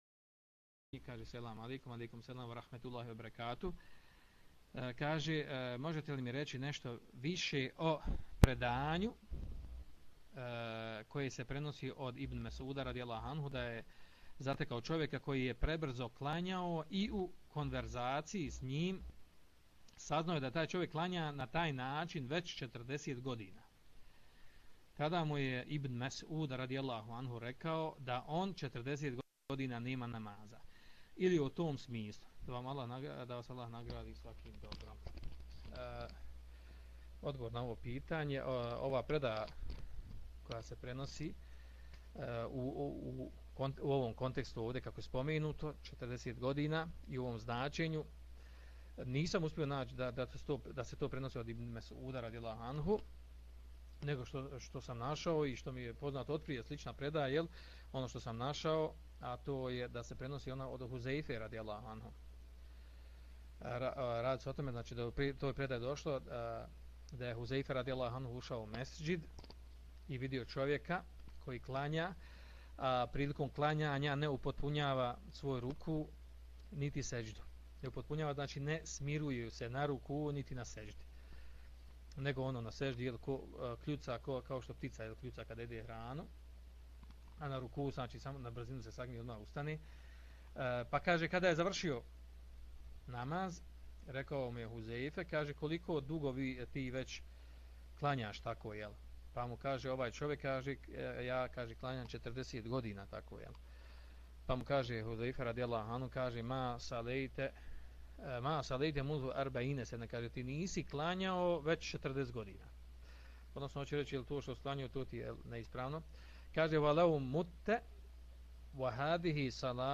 Audio mp3 isječak odgovora